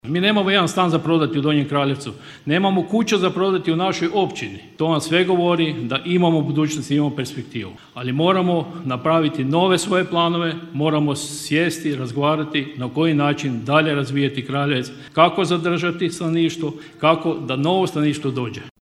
Svečana sjednica Općinskog vijeća Općine Donji Kraljevec održana u petak u općinskoj vijećnici bila je središnji događaj obilježavanja Dan općine, 7. svibnja.
Gospodarski razvoj svojih poduzetnika Općina prati ulaganjima u sve sfere društva, istaknuo je načelnik Miljenko Horvat: